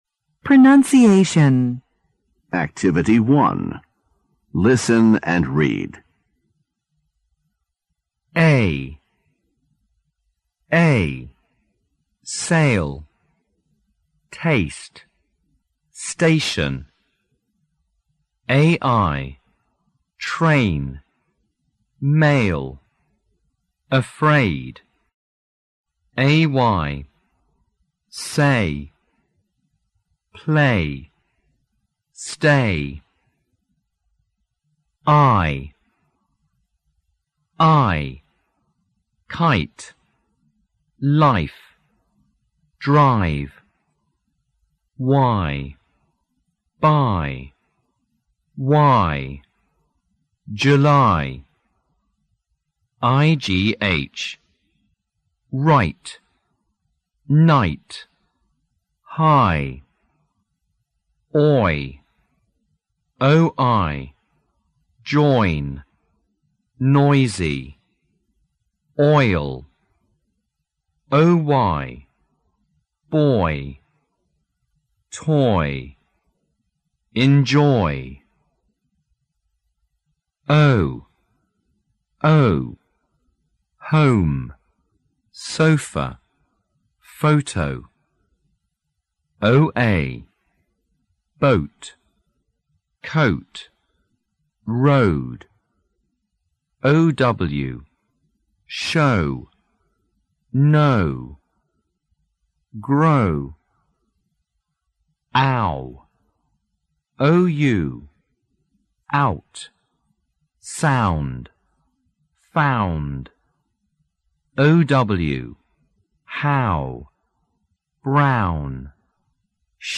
【素材】Unit 4 Don't eat in class第2课时——Pronunciation-1.mp3